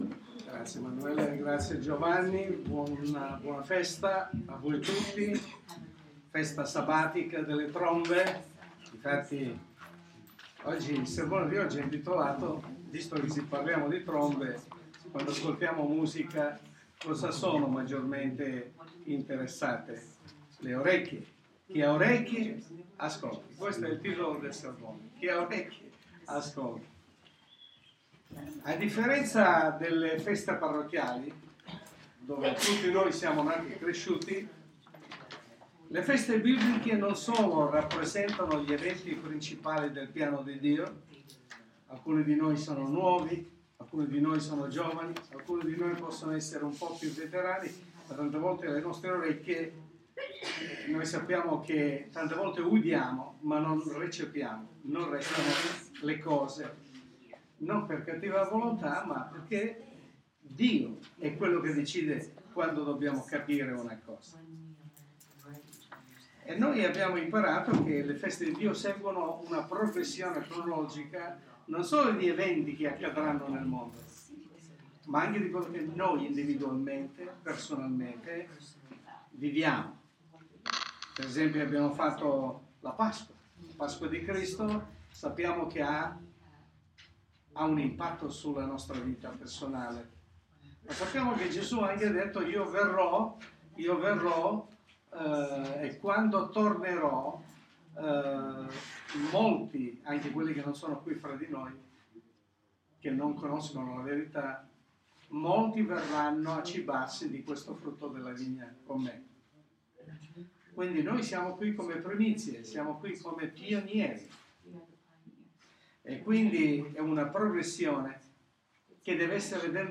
Sermone pastorale